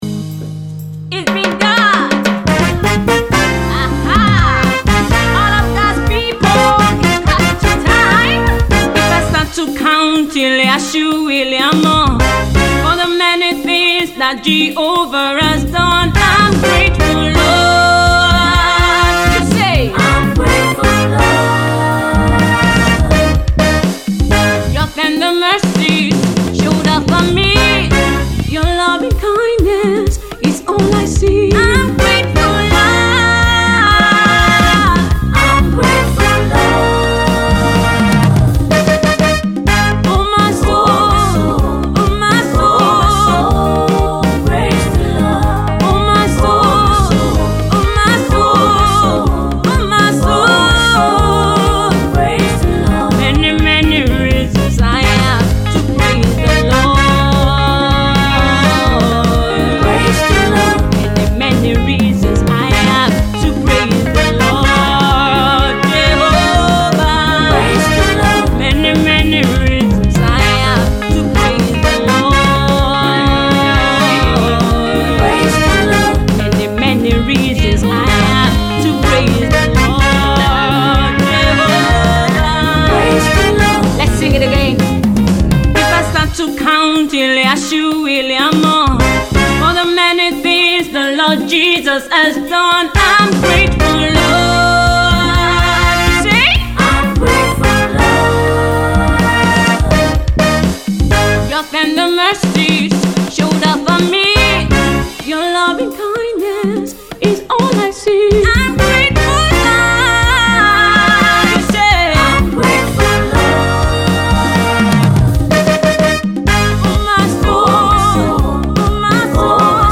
Amazing gospel singer and minister